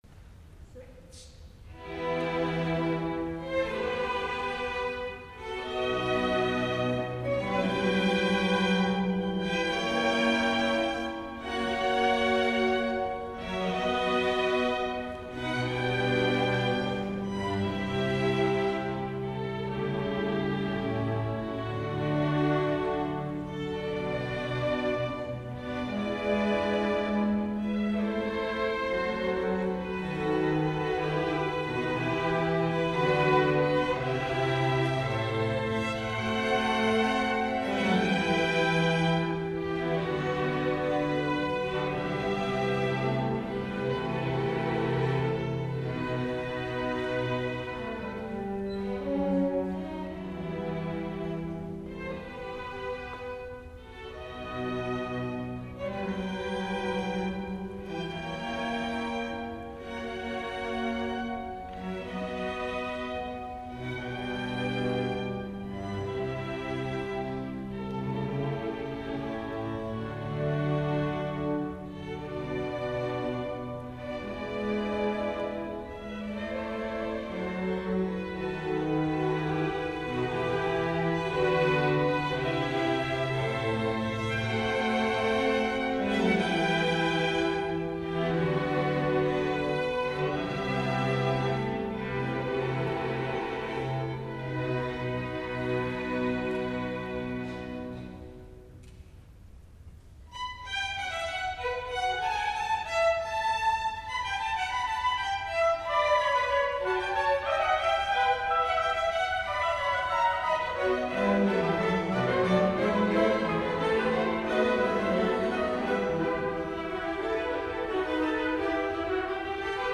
S. Gaudenzio church choir Gambolo' (PV) Italy
Chiesa Parrocchiale - Gambolò
Concerto di Natale